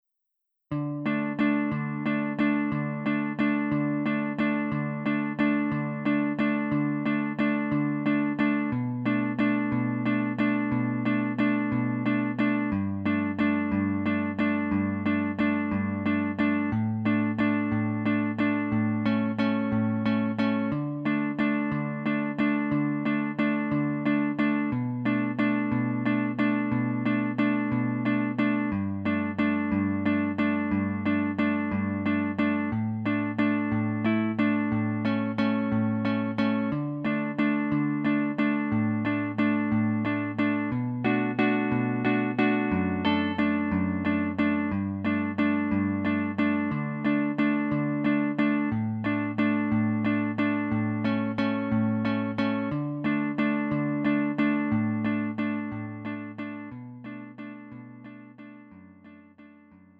음정 원키
장르 pop 구분 Lite MR